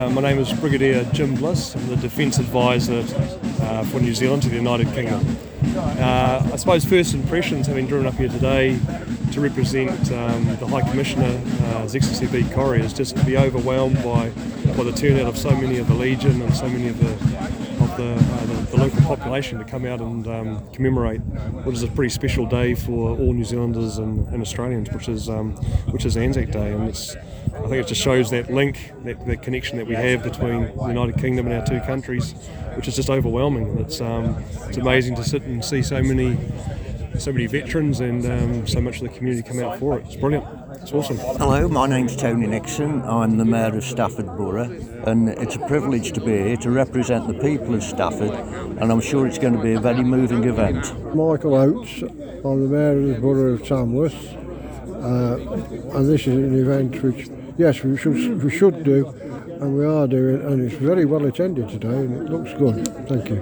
Each of the following links connect to audio recordings of some of the many dignitaries who attended the Service:
The Mayor of Stafford Borough, Councillor Tony Nixon
The Mayor of Tamworth Borough, Councillor Michael Oates